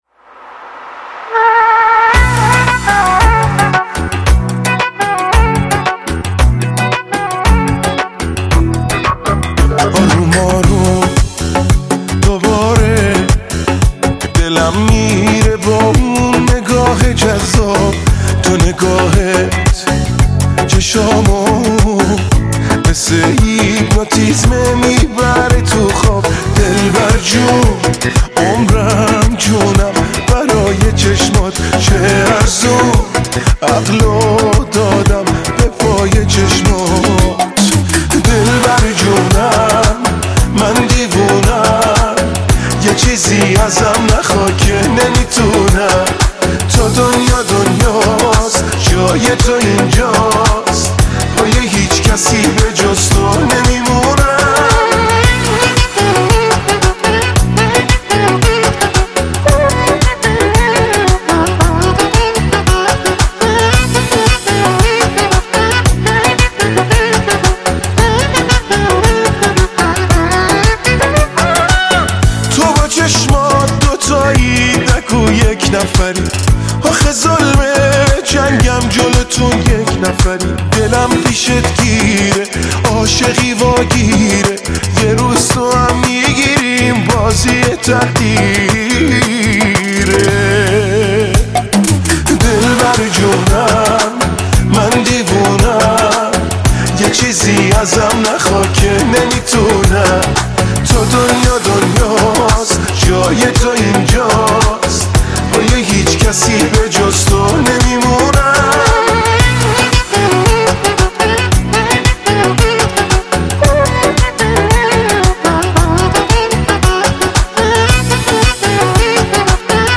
صدای خاص واسه ادمای خاص مثل همیشه عالییی